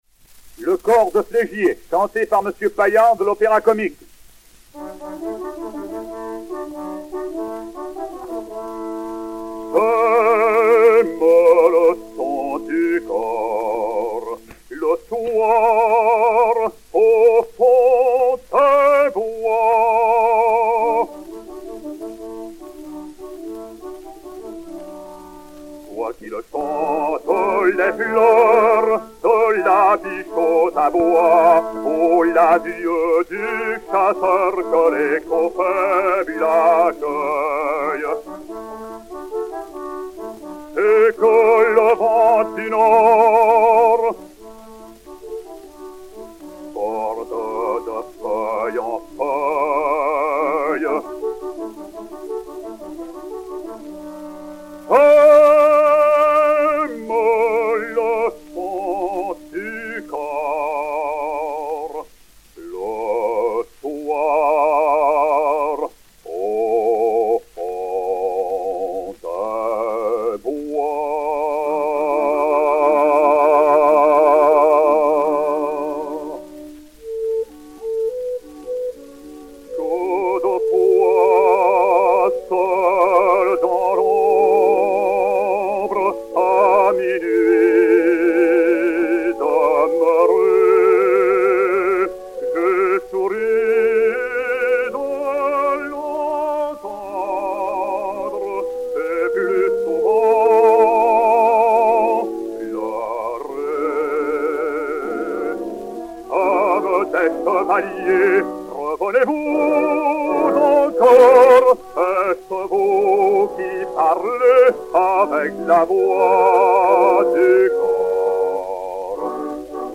basse française
poème pittoresque (par.
Orchestre